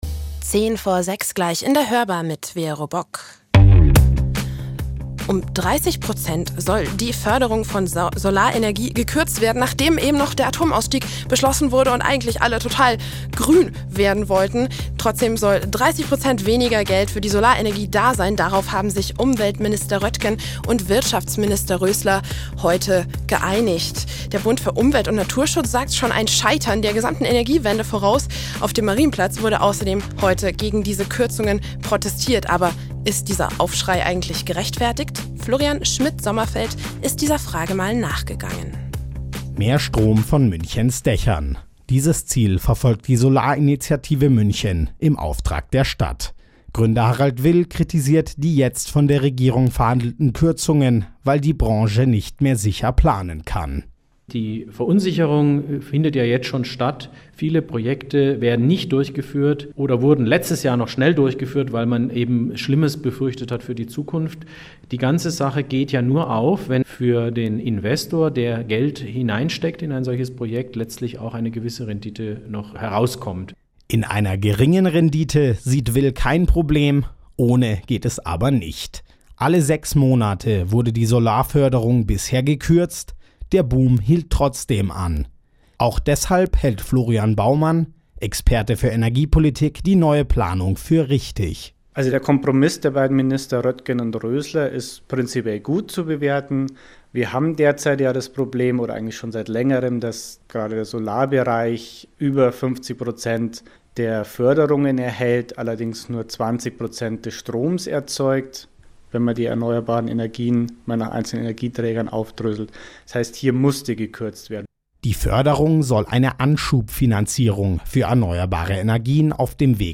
Beitrag mit Statements